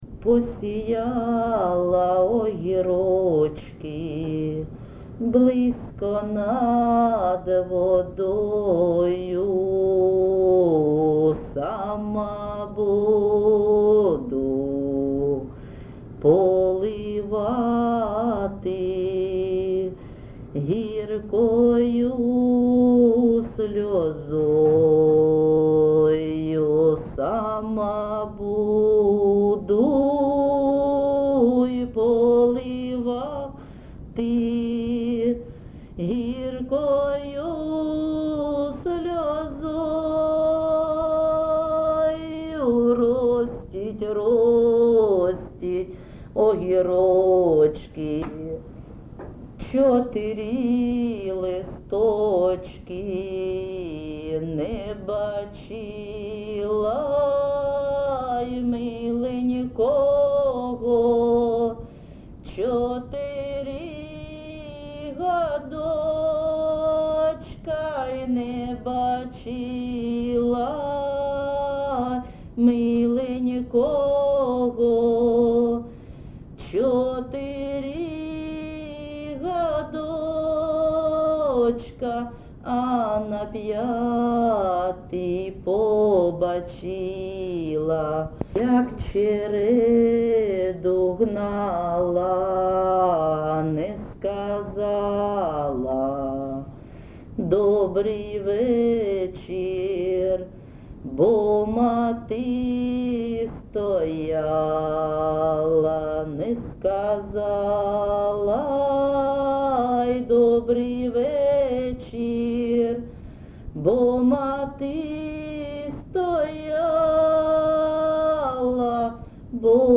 ЖанрПісні з особистого та родинного життя
Місце записус-ще Олексієво-Дружківка, Краматорський район, Донецька обл., Україна, Слобожанщина